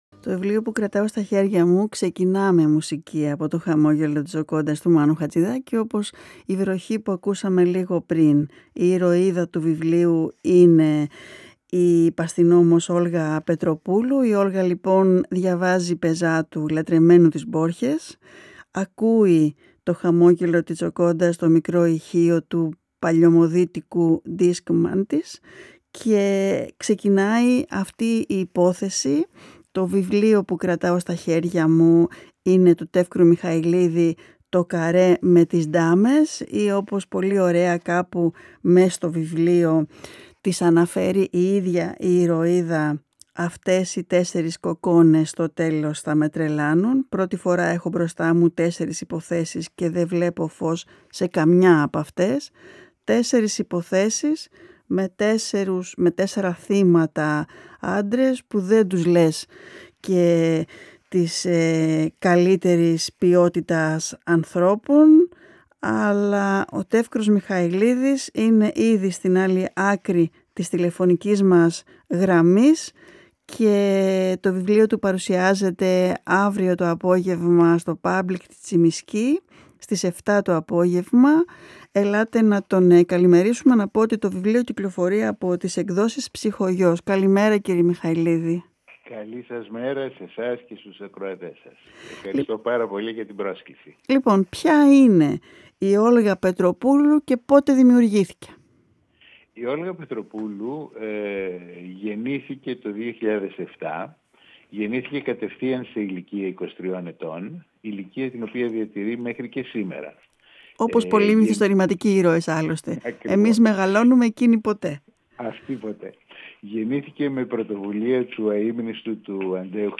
Συνέντευξη με τον Τεύκρο Μιχαηλίδη για το βιβλίο του “Το καρέ με τις ντάμες” (εκδ.